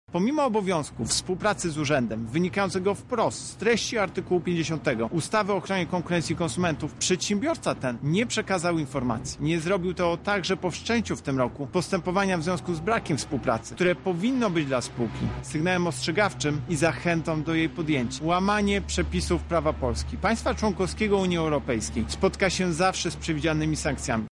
W trakcie postępowania zwróciłem się do Gazpromu o udostępnienie urzędowi umów zawartych z pozostałymi firmami finansującymi budowę Nord Stream 2 — mówi prezes UOKiK Tomasz Chróstny